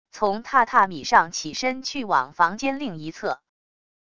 从榻榻米上起身去往房间另一侧wav音频